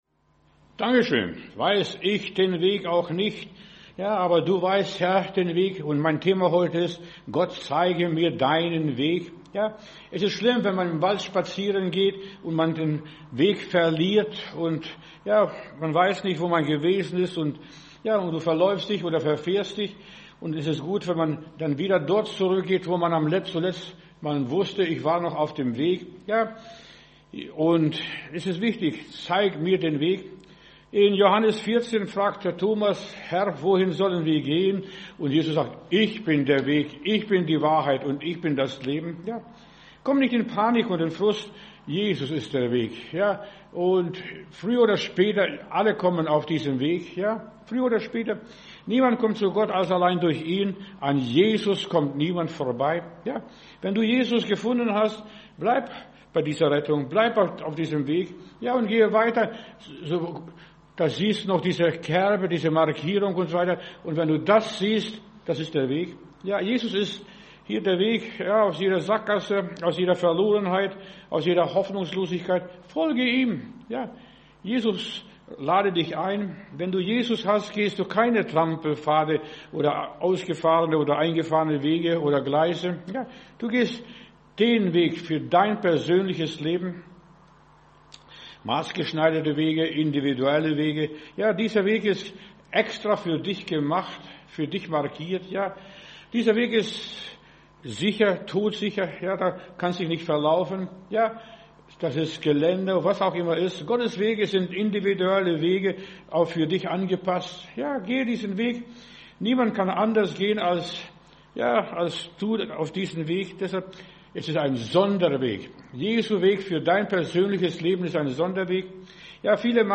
Wer uns unterstützen möchte, kann dies hier tun: ♥ Spenden ♥ Predigt herunterladen: Audio 2026-01-30 Zeig mir den Weg Video Zeig mir den Weg Facebook Twitter WhatsApp Telegram Email